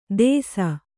♪ dēsa